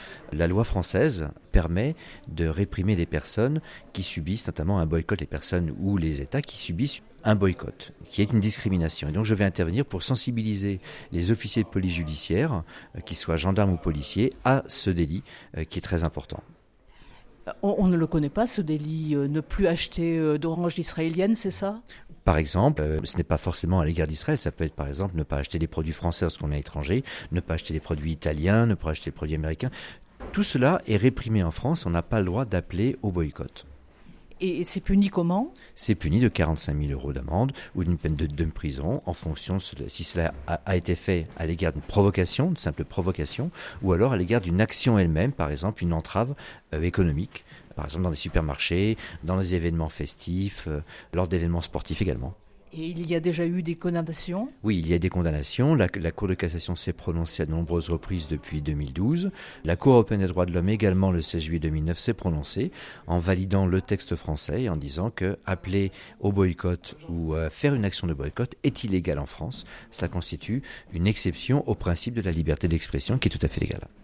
Une journée de formation -organisée par la Dilcrah et co-pilotée avec les ministères de l’Intérieur et de la Justice, en partenariat avec le Camp des Milles- s’est tenue le 1er juillet en Préfecture de région Provence-Alpes-Côte d’Azur à Marseille.